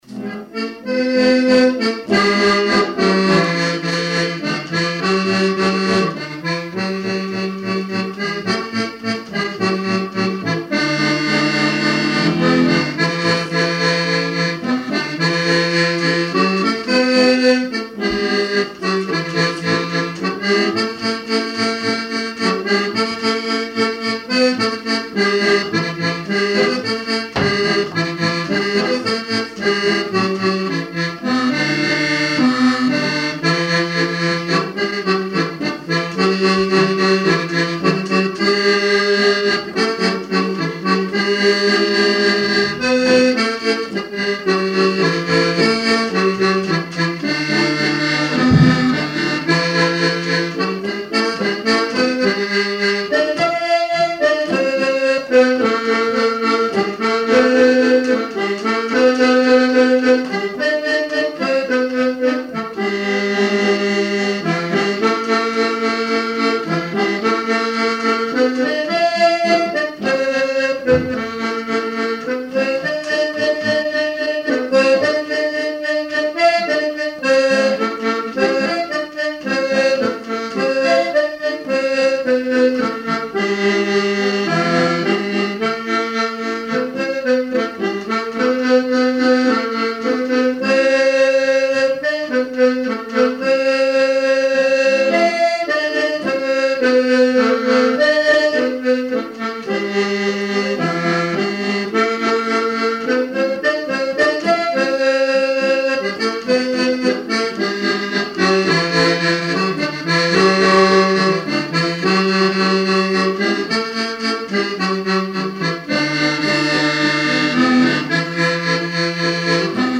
Mémoires et Patrimoines vivants - RaddO est une base de données d'archives iconographiques et sonores.
Répertoire de chansons populaires et traditionnelles
Pièce musicale inédite